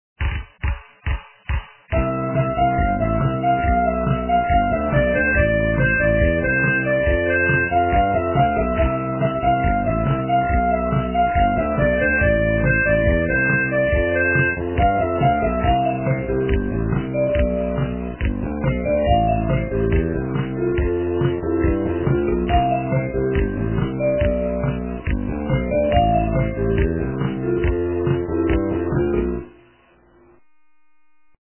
- русская эстрада
качество понижено и присутствуют гудки